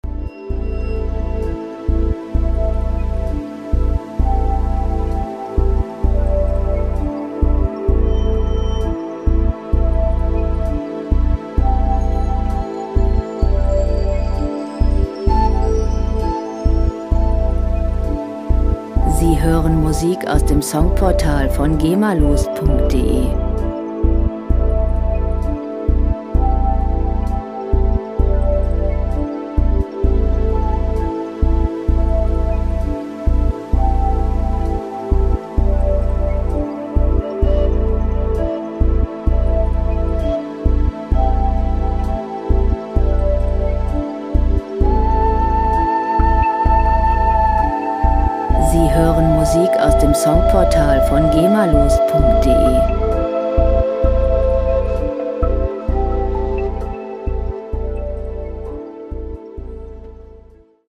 • Hypnose-Musik
Musik für die Hypnose-Therapie 49,00 EUR